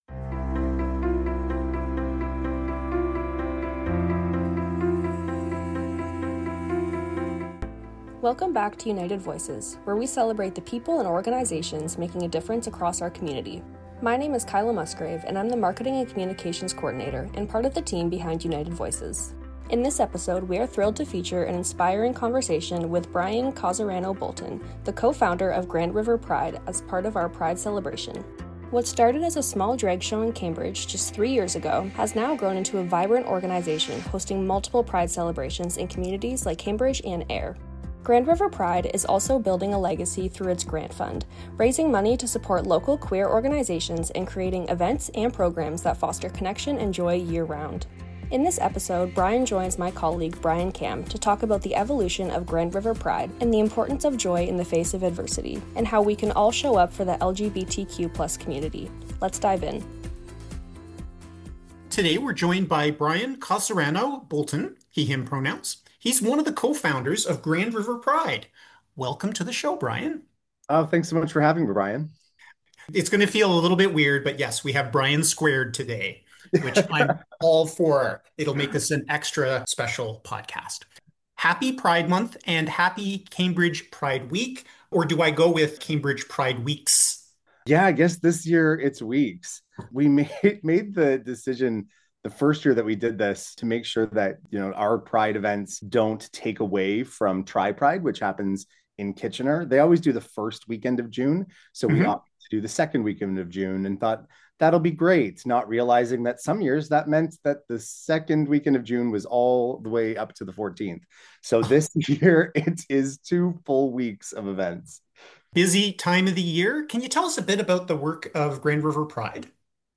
Episode 2: Amplifying Support in the Community - Langs at MACSIn this episode of United Voices, we take a deep dive into the vital work being done at the Multi-Agency Community Space (MACS), a hub of collaboration and support in our community. This conversation